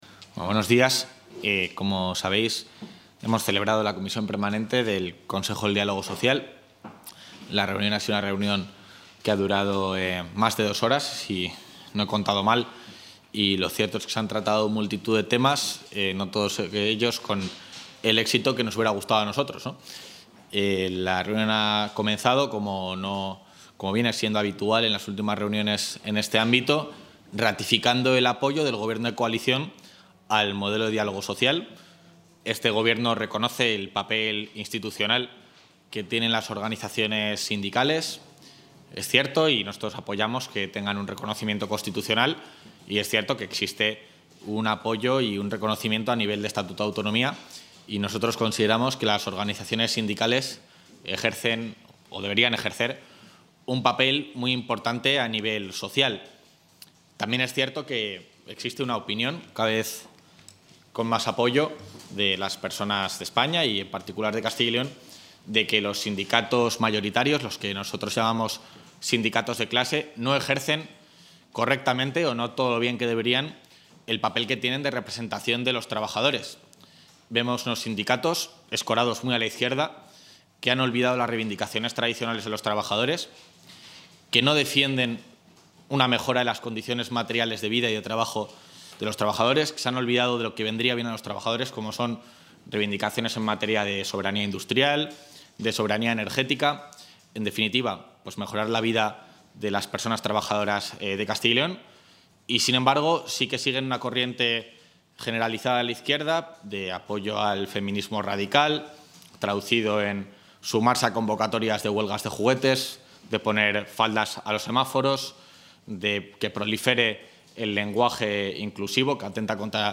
Intervención vicepresidente.